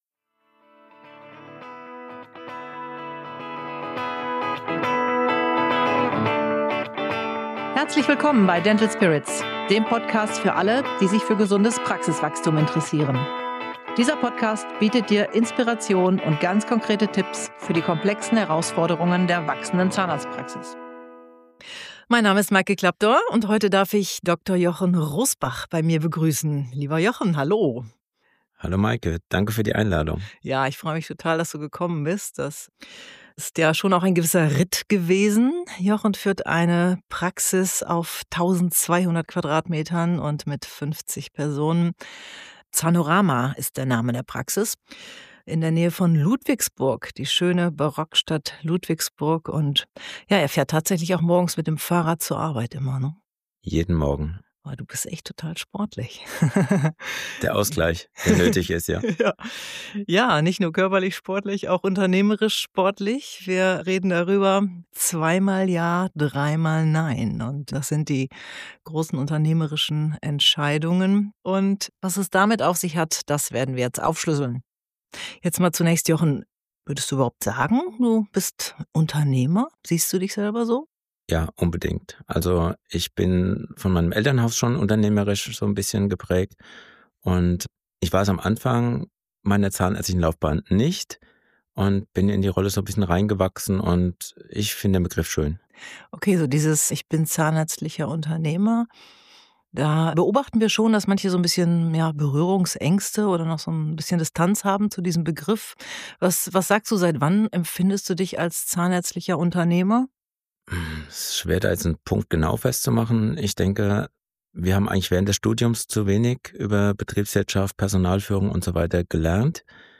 Viel Freude mit diesem erfrischenden Talk durch das breite Spektrum zahnärztlich-unternehmerischen Wirkens.